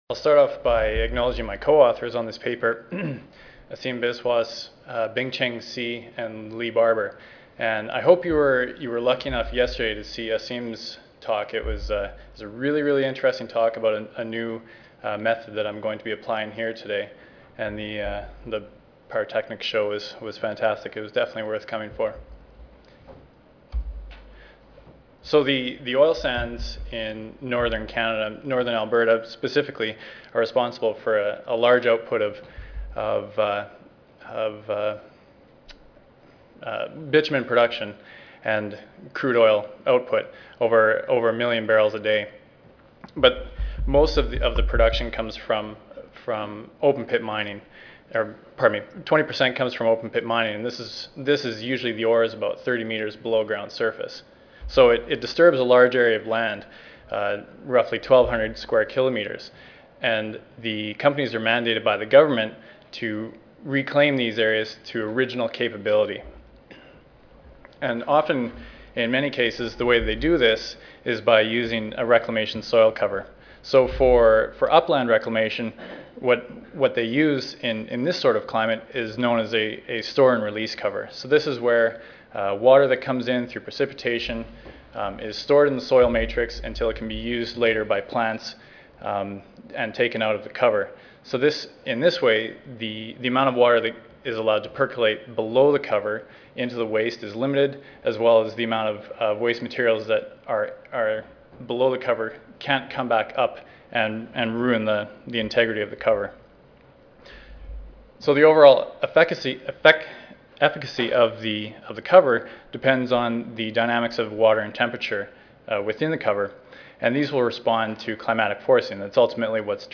University of Saskatchewan Audio File Recorded presentation